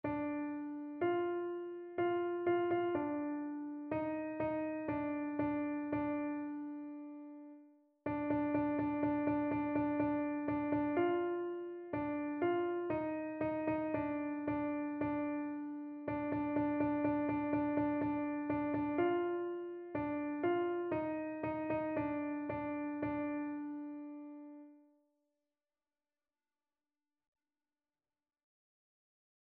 messe-de-saint-andre-sanctus-alto.mp3